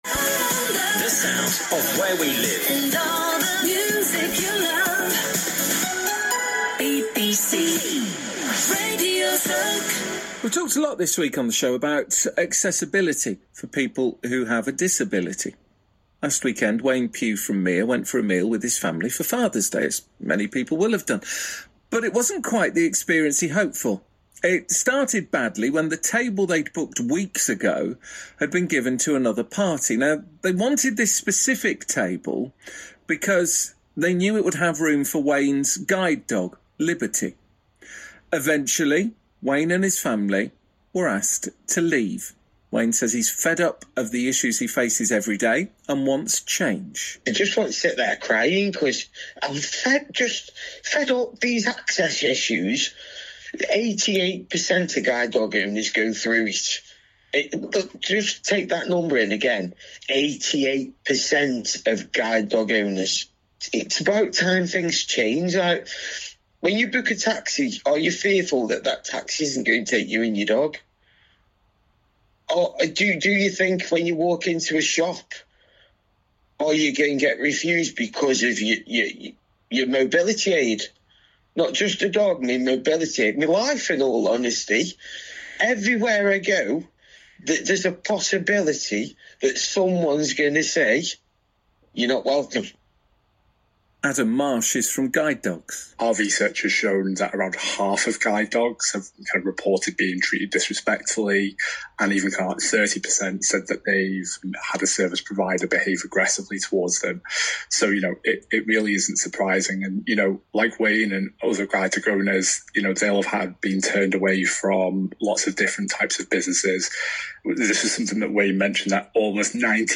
Interview on simple ideas for making businesses accessible